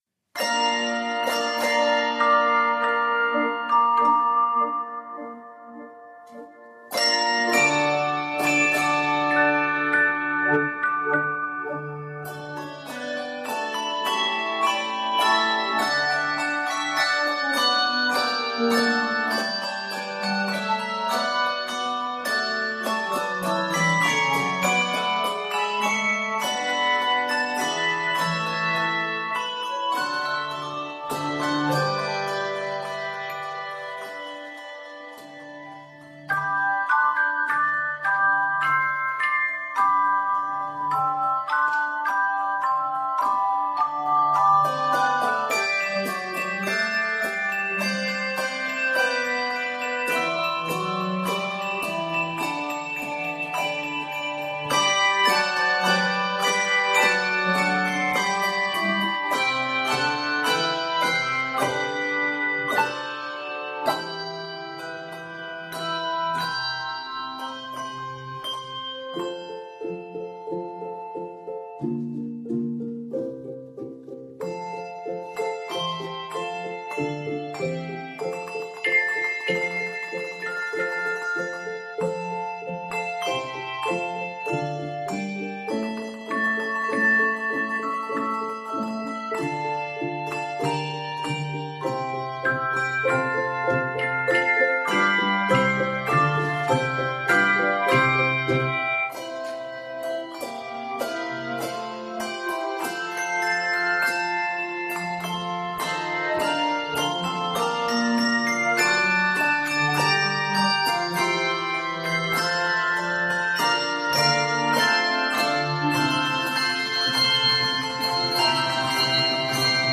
this arrangement of favorite American hymns